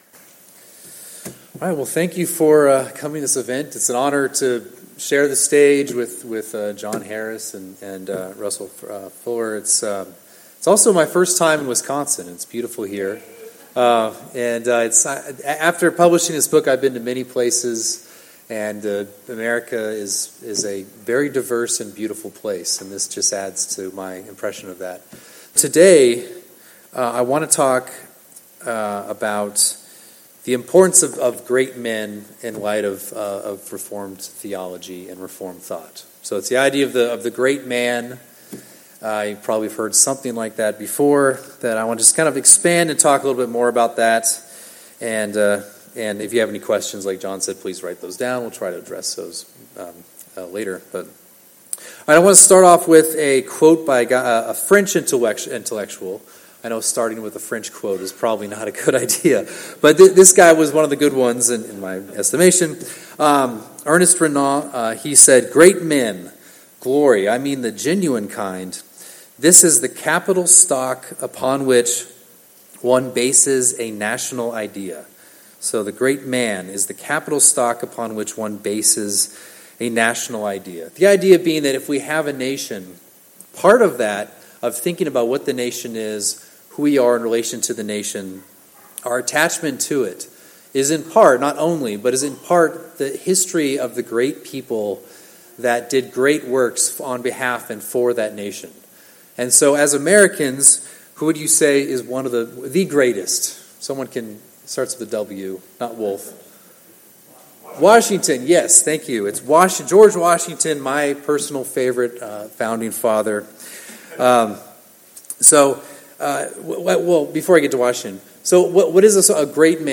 Service Type: Special Speaker Topics: Civil Magistrates , Great Men